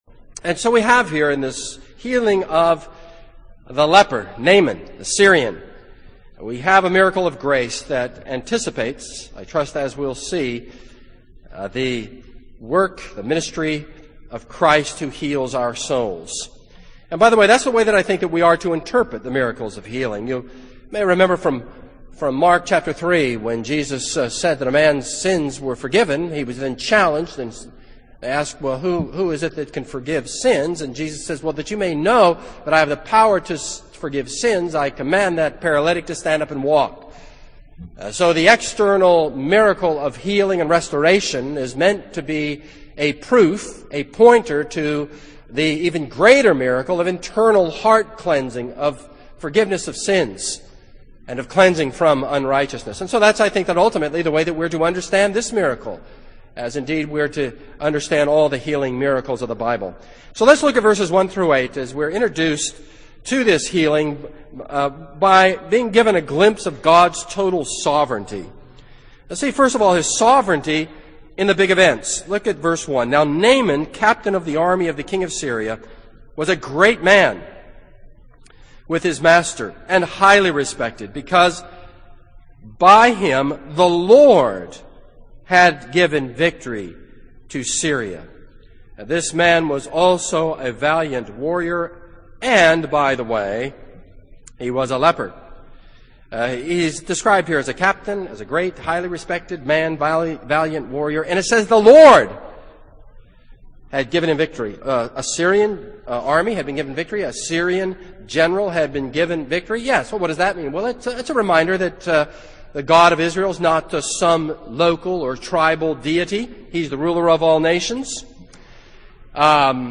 This is a sermon on 2 Kings 5:1-14.